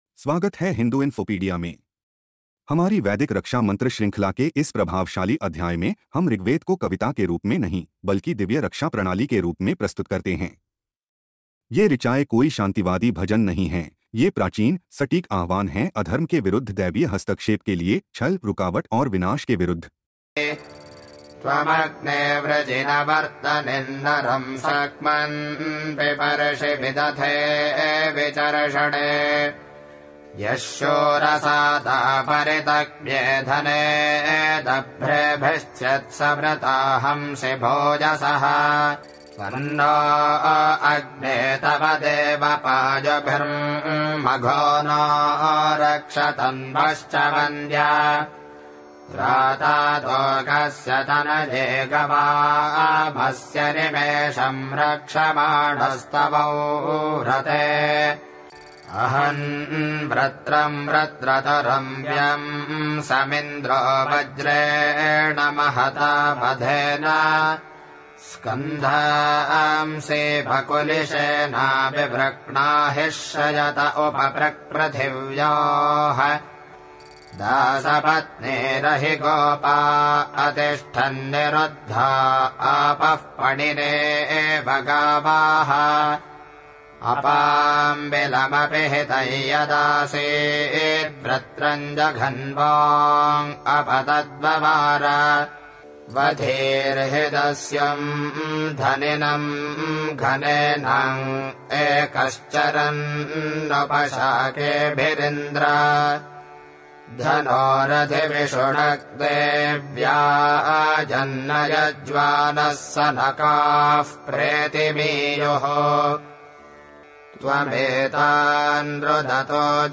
Learn how to chant